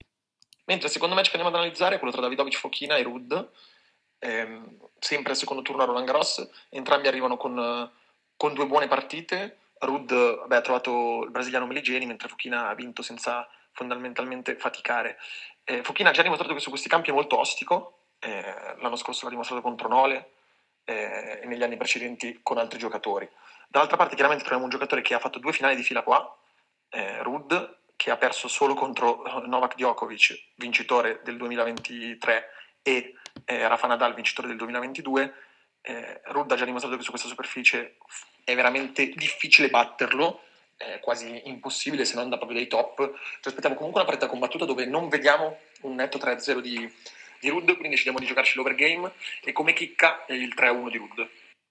Ecco l’audio analisi con i consigli per le scommesse sul Tennis